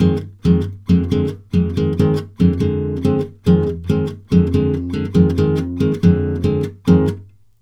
140GTR FM7 2.wav